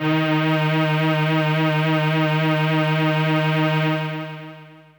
55bd-syn08-d#3.aif